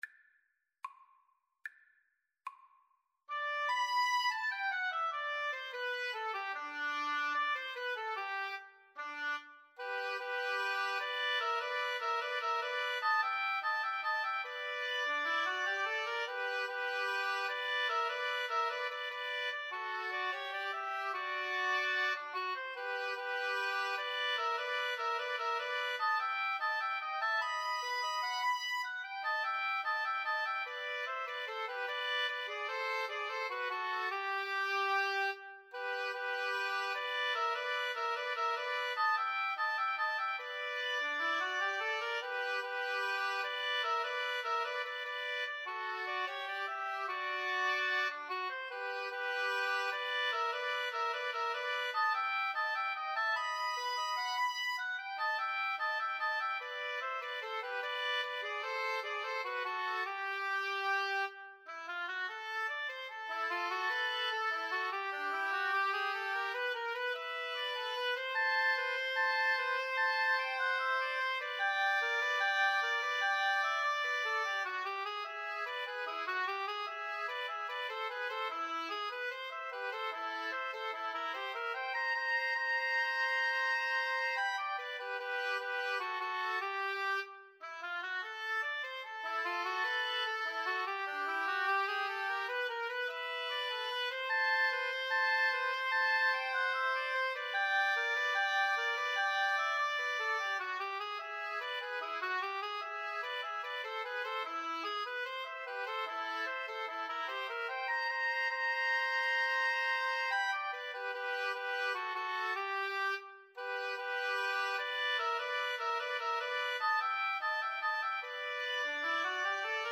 Oboe 1Oboe 2Cor Anglais
Not Fast = 74
2/4 (View more 2/4 Music)
Jazz (View more Jazz Woodwind Trio Music)